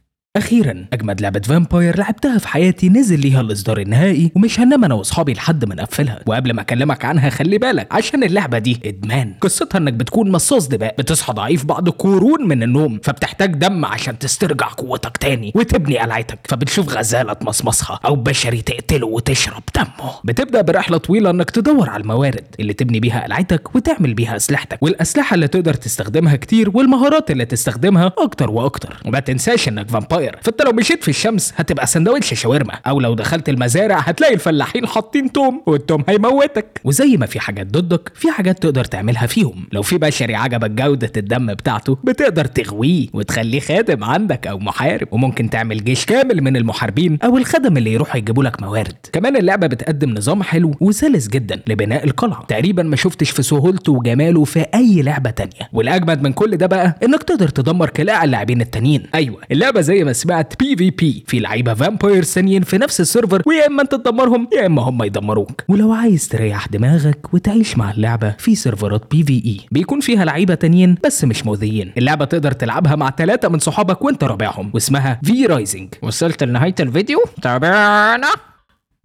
Male
Yng Adult (18-29), Adult (30-50)
Narration
A Voiceover For A Tiktok
All our voice actors have professional broadcast quality recording studios.